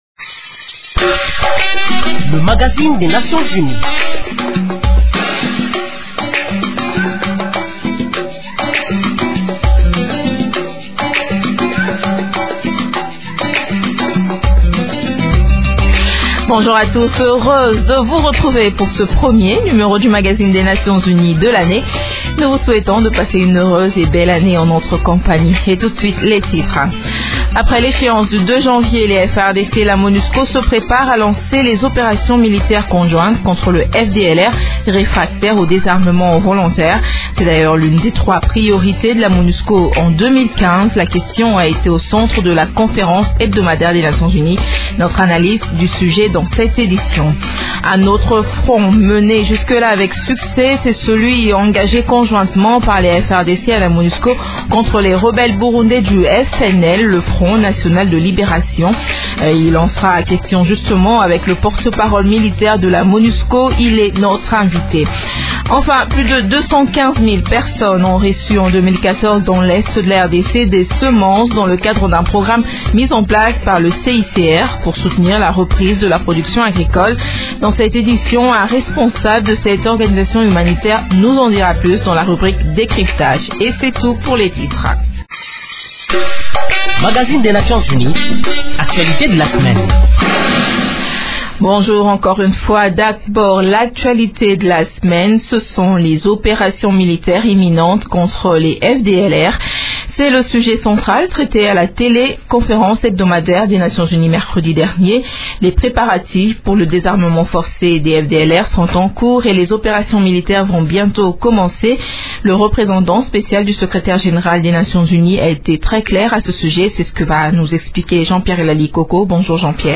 Le patron de la Monusco, Martin Kobler, a prononcé ces mots, mercredi 7 janvier lors de la conférence hebdomadaire de l’Onu, à Kinshasa.